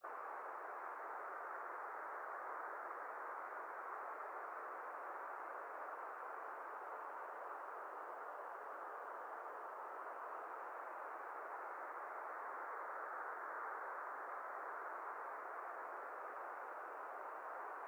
wind2.mp3